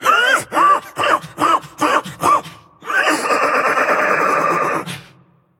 Fiddlesticks_Original_Laugh